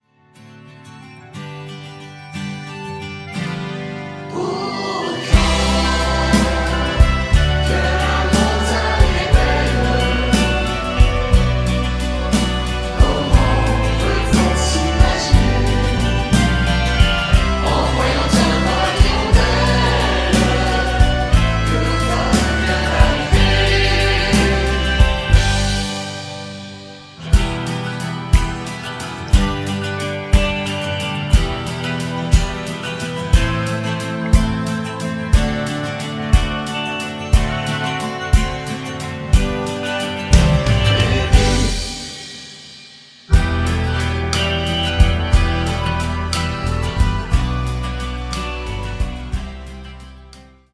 avec choeurs au refrain